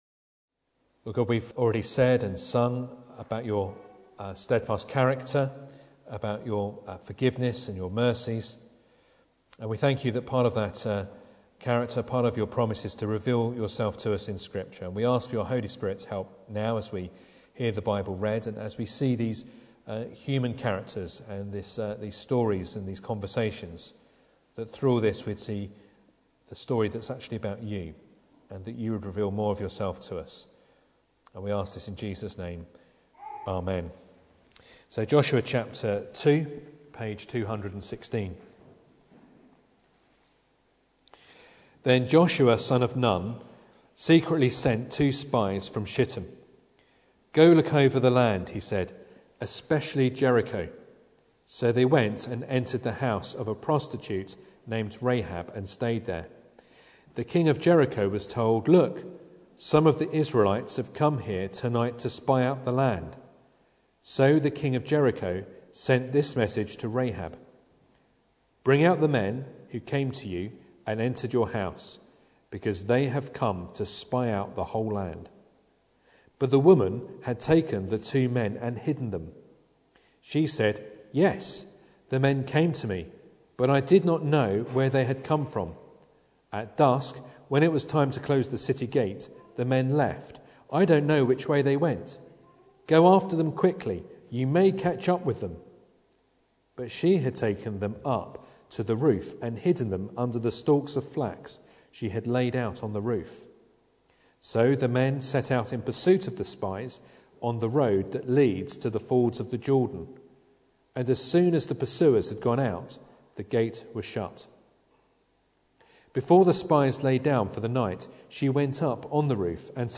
Joshua 2 Service Type: Sunday Evening Bible Text